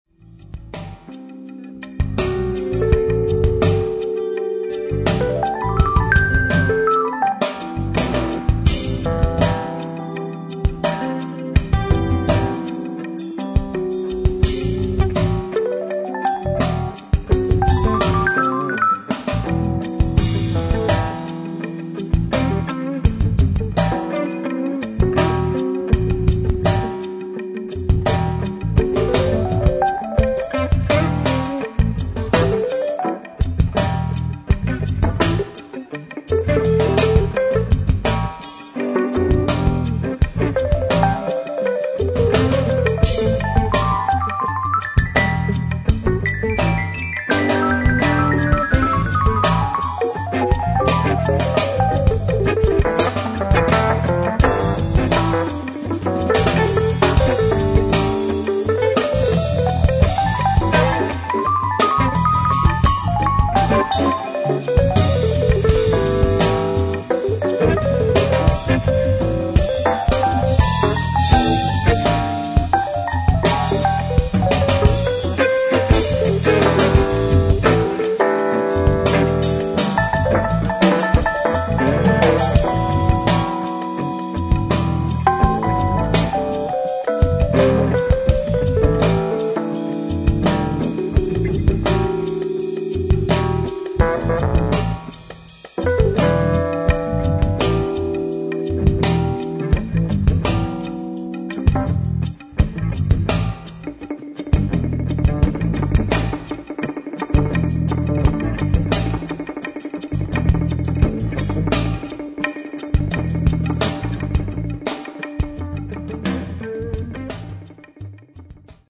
Mixed bag of solo excerpts    [ top ]
fender rhodes